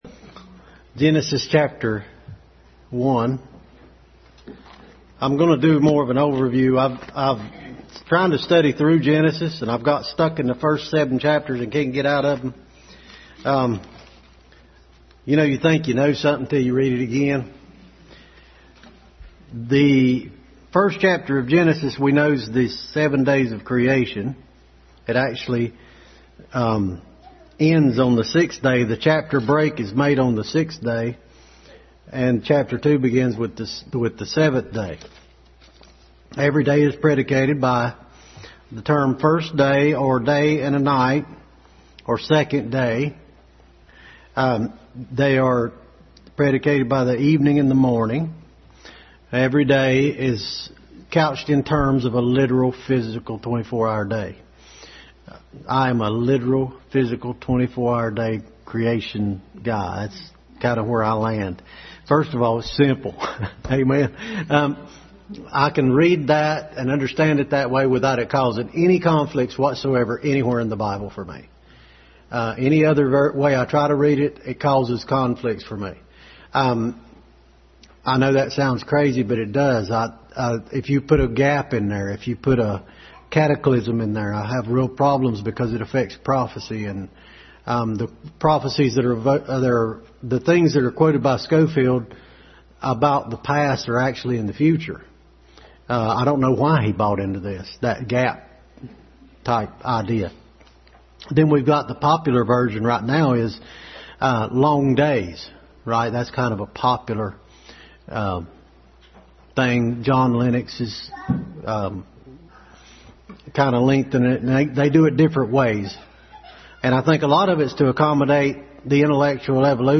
Family Bible Hour Message.
Genesis 1-2 Overview Passage: Genesis 1-2 Service Type: Family Bible Hour Family Bible Hour Message.